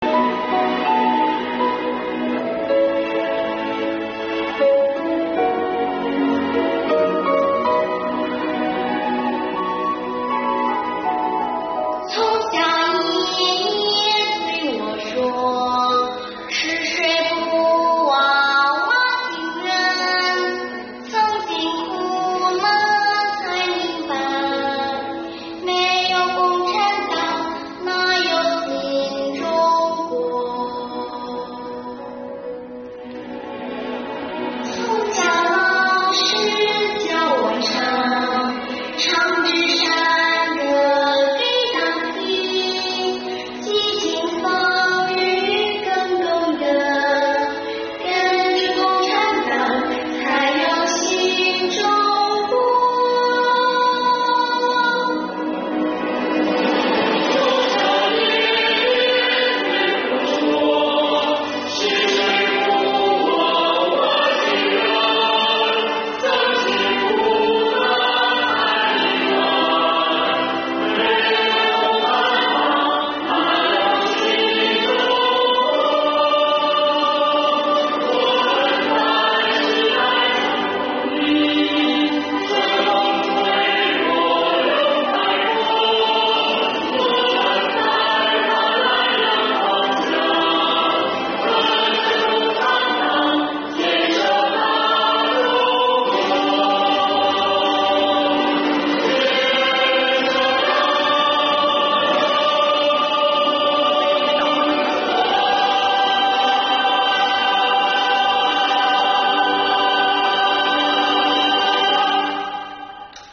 钦州市税务局情景歌舞剧《传承》